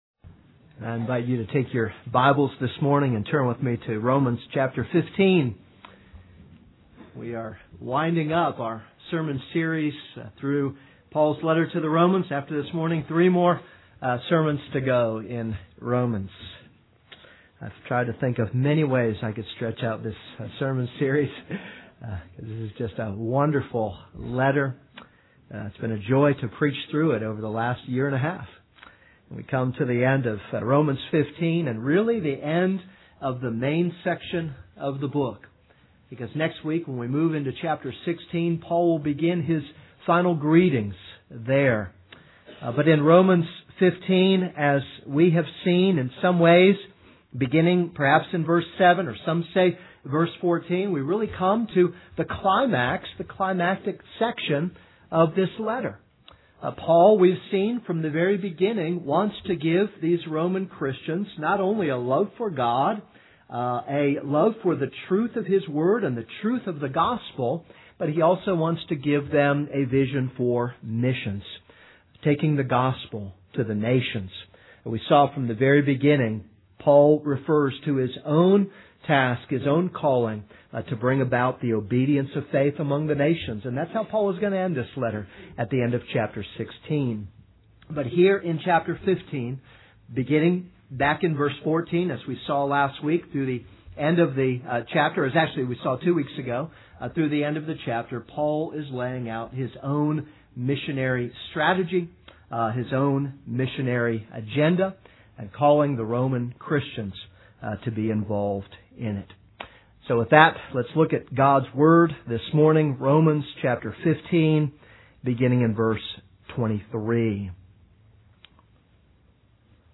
This is a sermon on Romans 15:23-33.